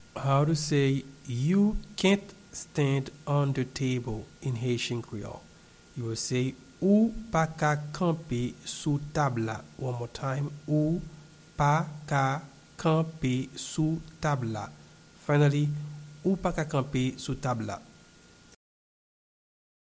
You-cant-stand-on-the-table-in-Haitian-Creole-Ou-pa-ka-kanpe-sou-tab-la-pronunciation.mp3